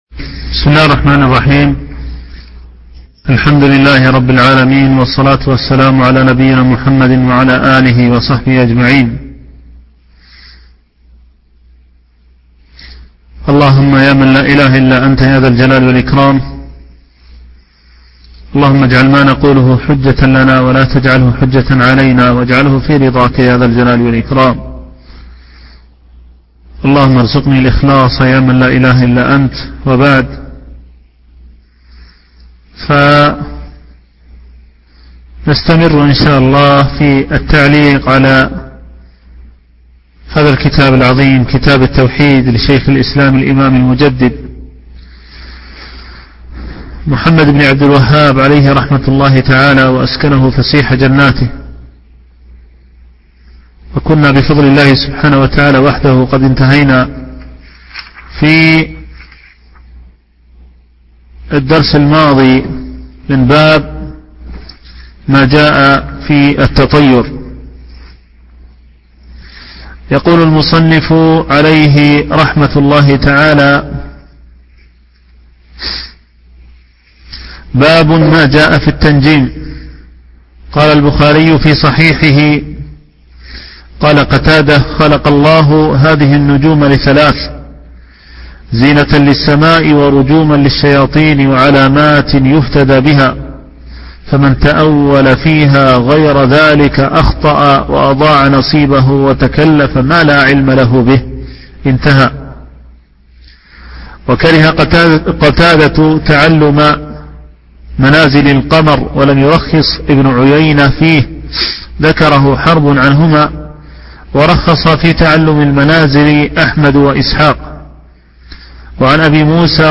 شرح كتاب التوحيد - الدرس السادس والعشرون